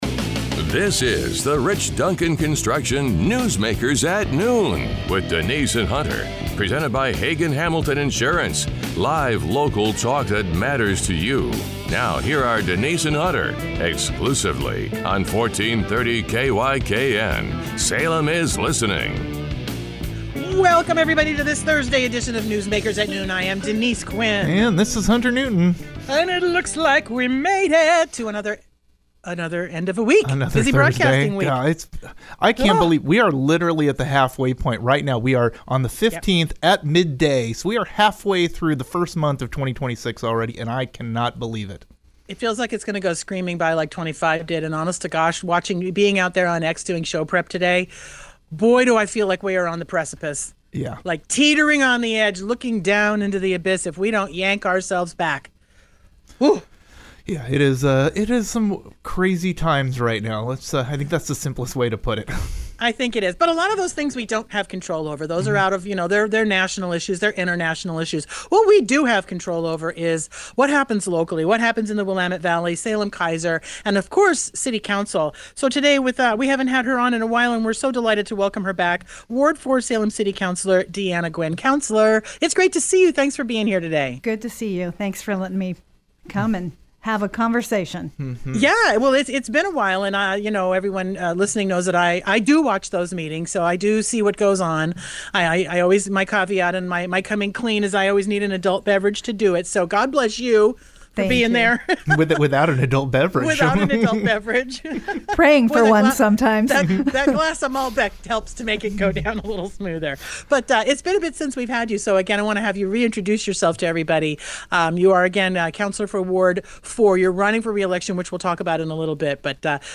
At 12:30, Danielle Bethell, Marion County Commissioner and Oregon gubernatorial candidate, joins the show to outline her education priorities, shaped by her time on the Salem-Keizer School Board, as well as how her business background and leadership as President of the Association of Oregon Counties inform her vision for governing Oregon.